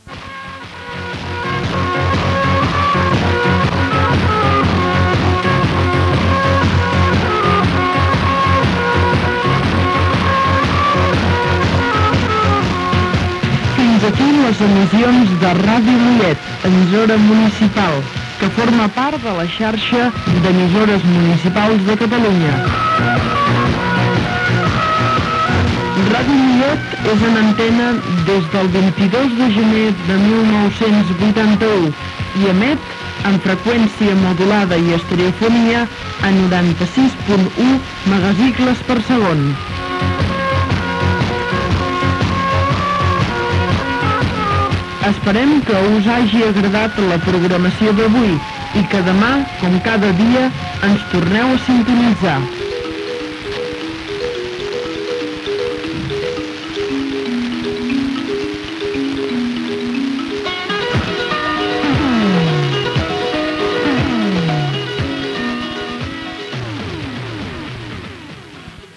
1de42e05d7b1548cbaeff8d1dbefe5c19fd446c4.mp3 Títol Ràdio Mollet Emissora Ràdio Mollet Titularitat Pública municipal Descripció Tancament de l'emissió.